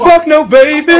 pause-back-click.wav